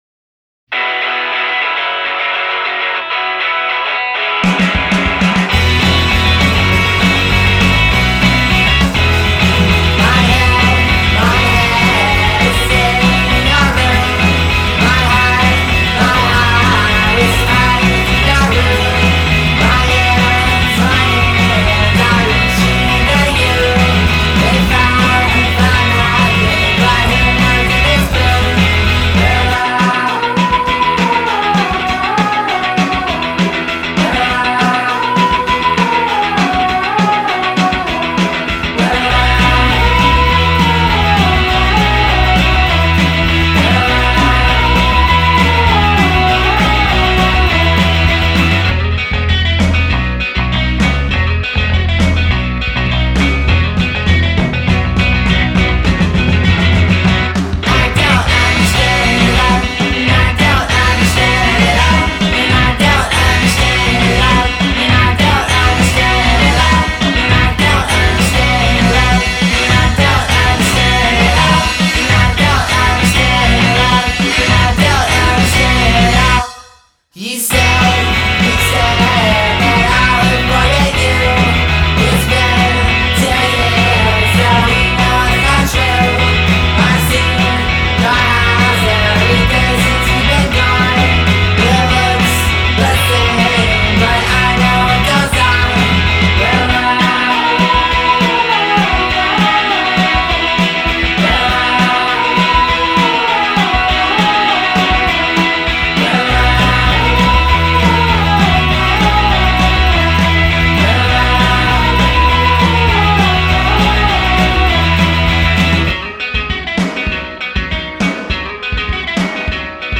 jangle fuzz sound
scuzzy jangle-pop